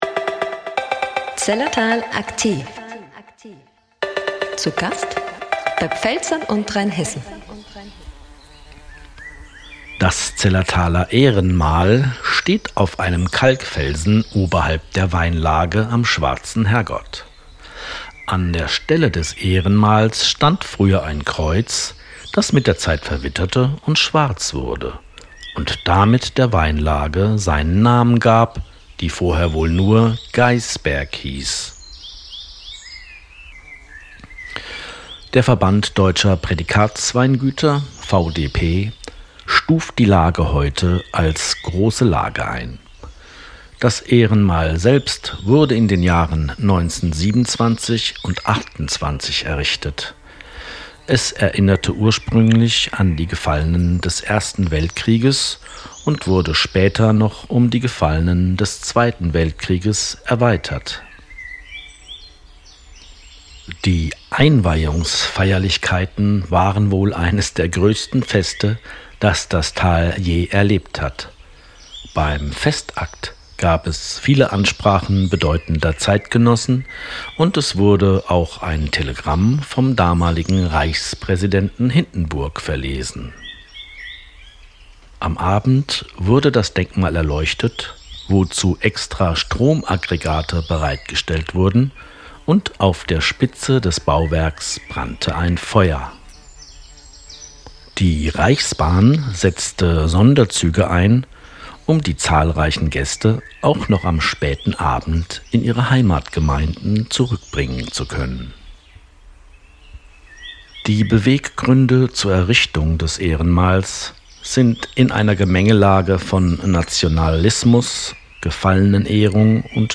AUDIO-GUIDES